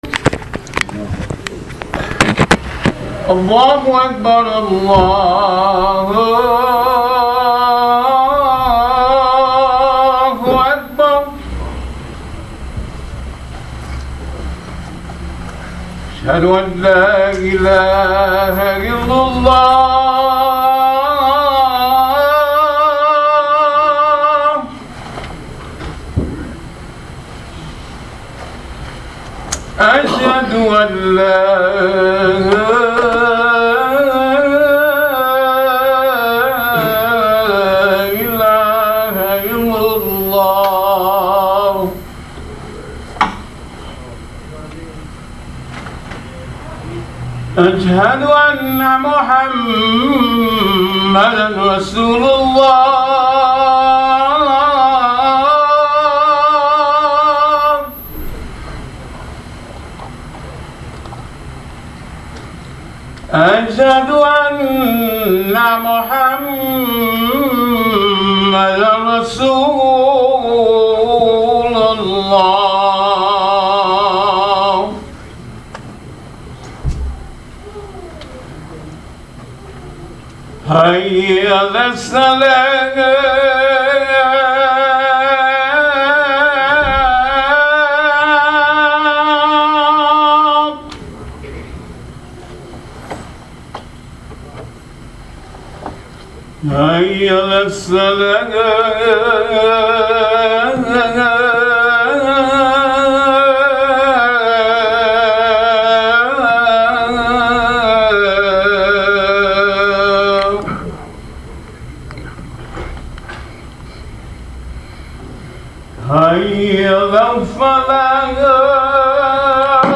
This is the topic of this khutbatul Jum’ah at Masjid Ibrahim Islamic Center in Sacramento California.